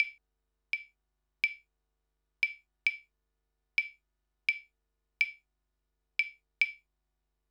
Instrumento percusión: palito marcando un ritmo latino
percusión
latino